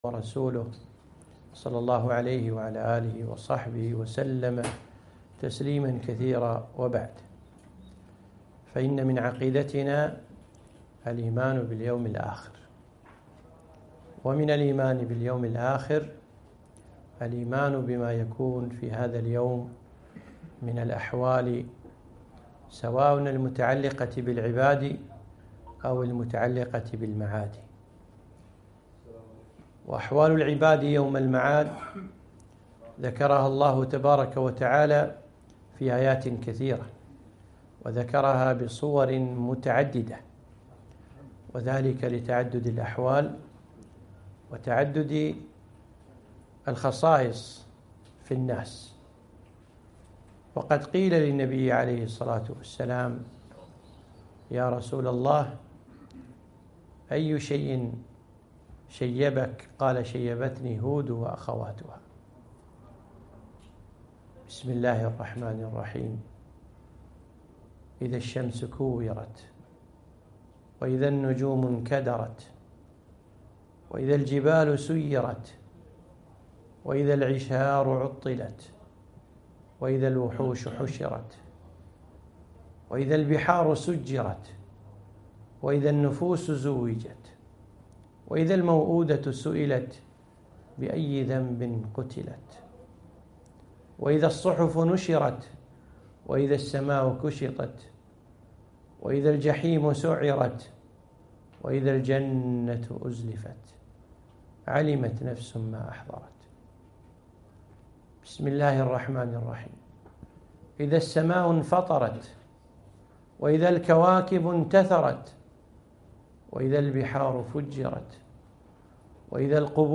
محاضرة - أحوال العباد يوم المعاد